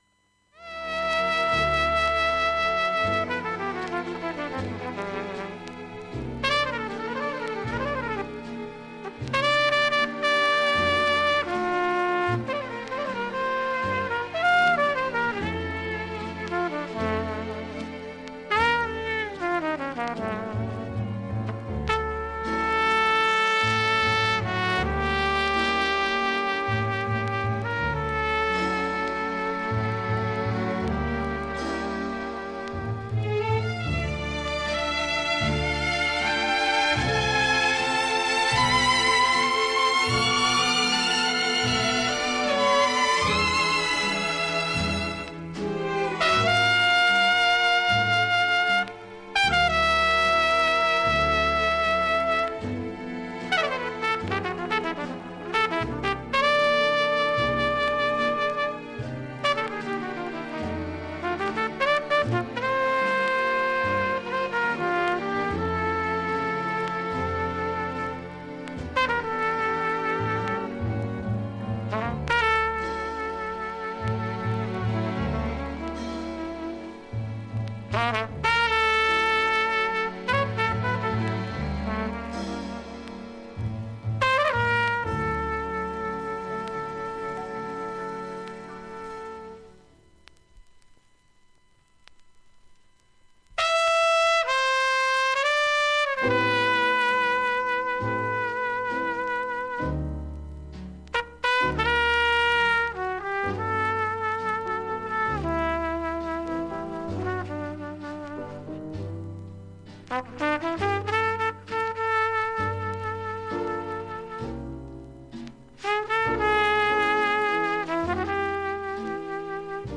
下記プツ音など小さめです。